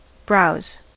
browse: Wikimedia Commons US English Pronunciations
En-us-browse.WAV